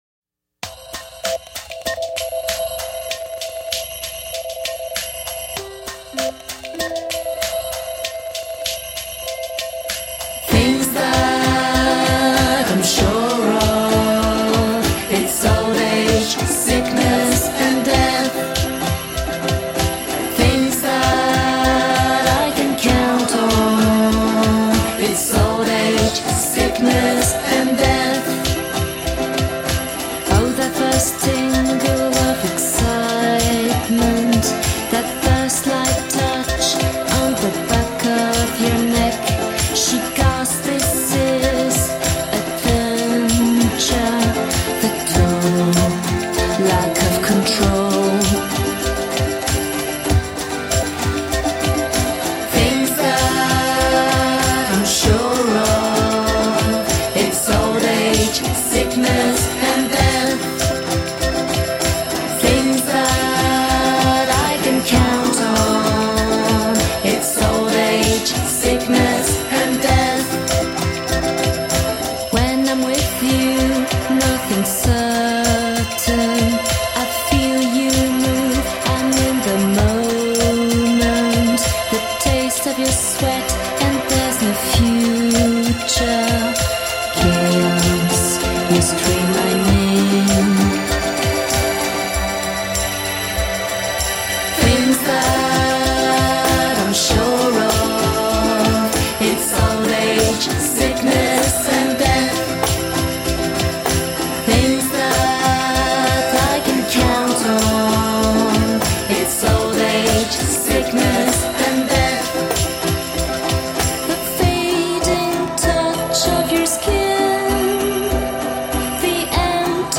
Punk rock to blow your head off.
Tagged as: Hard Rock, Punk, Instrumental, Intense Metal